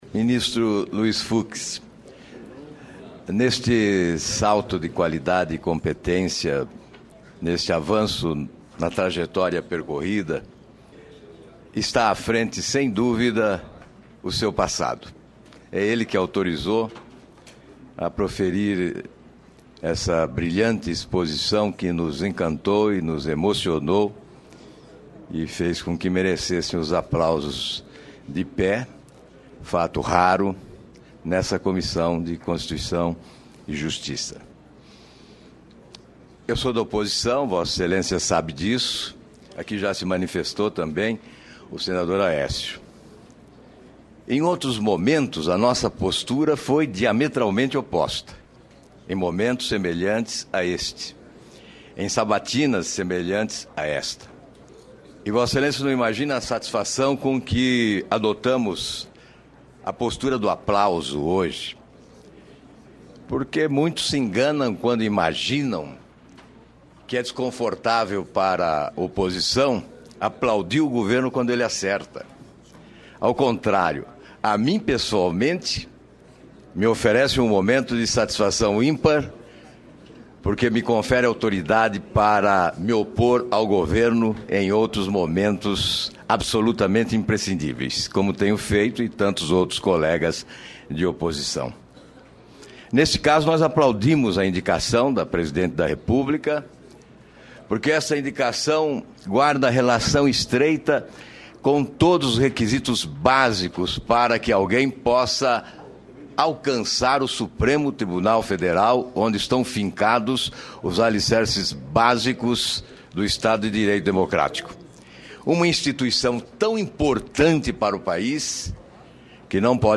Sabatina do indicado a ministro do STF Luiz Fux - 10ª parte